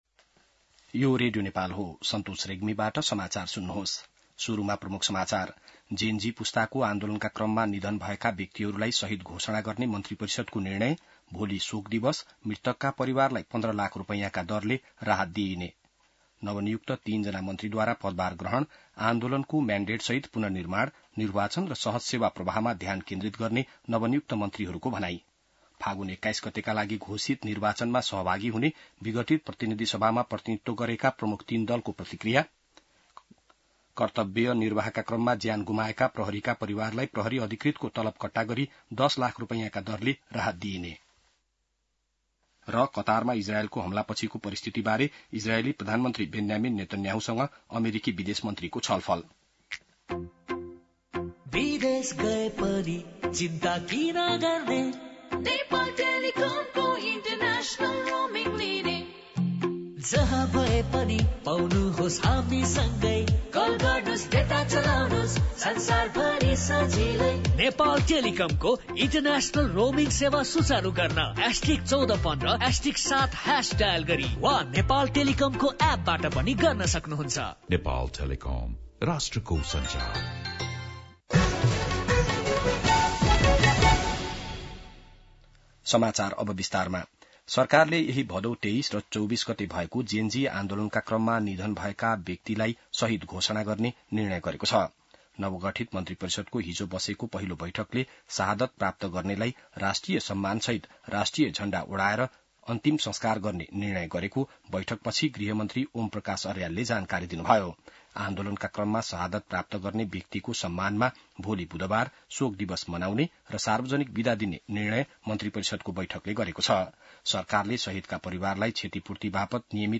बिहान ७ बजेको नेपाली समाचार : ३१ भदौ , २०८२